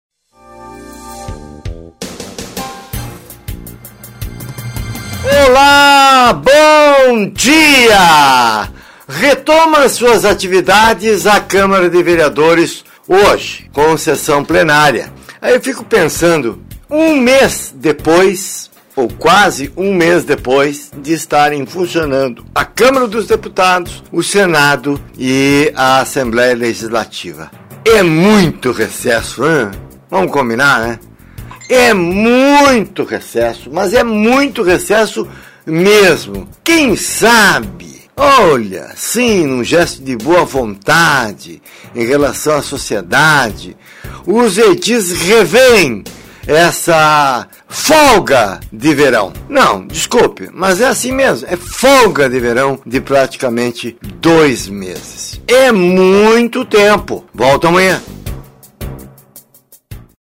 Diariamente, de segunda a sexta, ele é reproduzido aqui, com a autorização da direção da emissora.